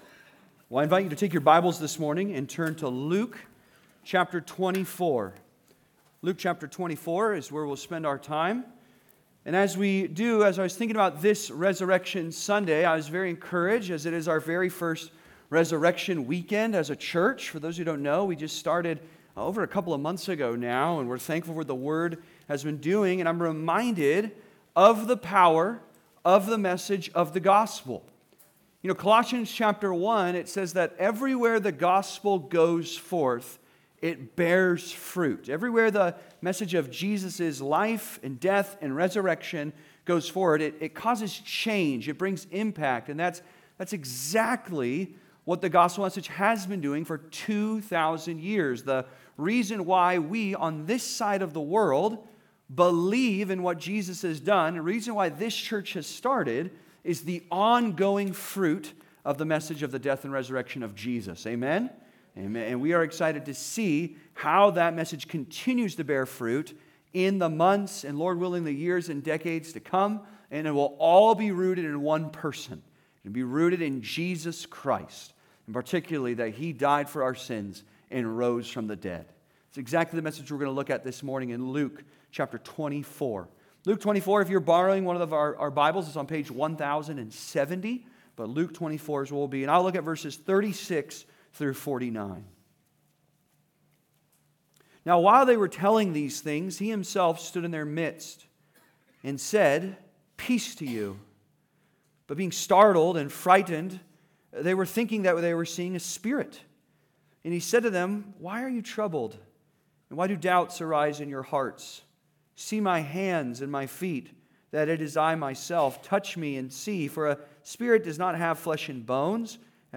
Resurrection Confidence (Sermon) - Compass Bible Church Long Beach